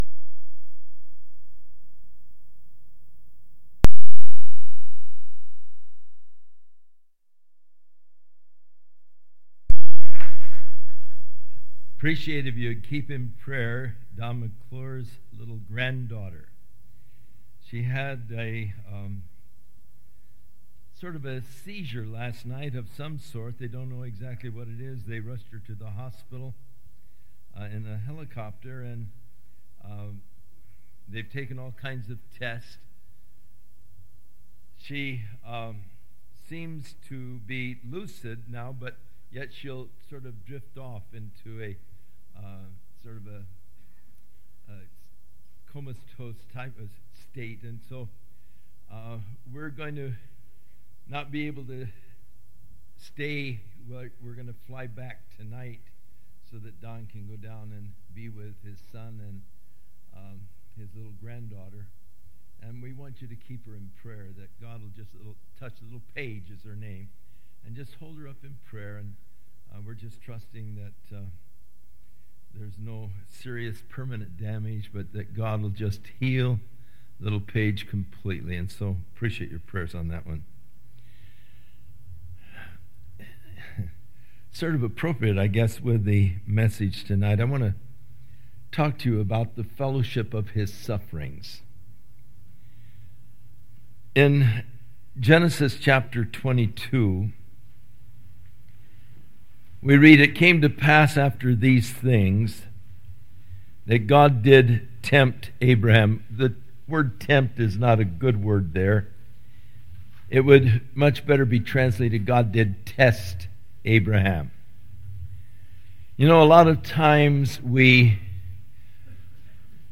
Session 9 Speaker: Chuck Smith Series: 2000 DSPC Conference: Pastors & Leaders Date: January 20, 2000 Home » Sermons » Session 9 Share Facebook Twitter LinkedIn Email Topics: Session 9 « Session 8 Session 10 »